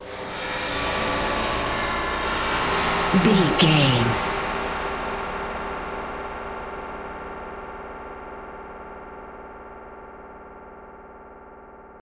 Atonal
Slow
tension
ominous
dark
haunting
eerie
synthesiser
keyboards
ambience
pads
eletronic